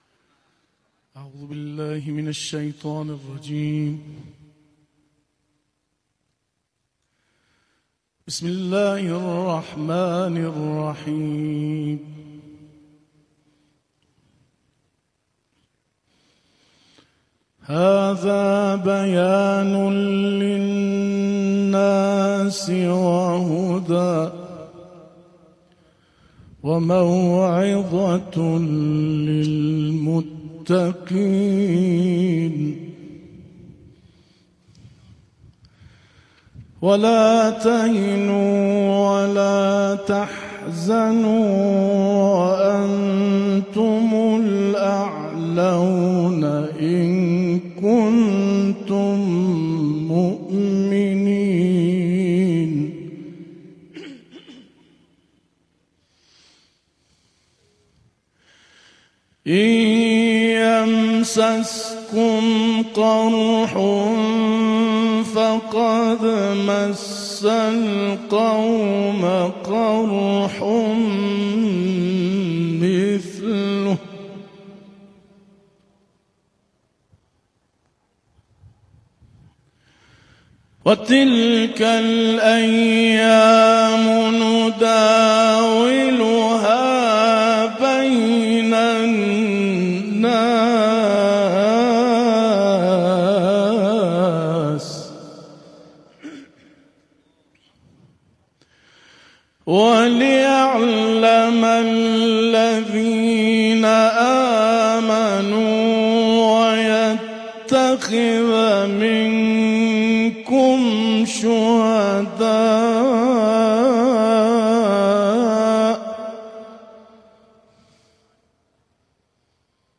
Rezitation der Verse 138 bis 150 der Sure al-Imran
Diese Rezitation fand im Rahmen der Sammlung „Sieg“ zur Vertrautmachung mit dem Koran statt. Diese Sammlung fand am 9. Juli in Anwesenheit der Korangemeinde des Landes am Grab des Märtyrers General Amir Ali Hajizadeh, verstorbener Kommandeur der Luft- und Raumfahrtstreitkräfte der IRGC, und gleichzeitig in Teheran und auf Märtyrerfriedhöfen anderer Städte des Landes statt.